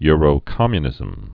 (yrō-kŏmyə-nĭzəm)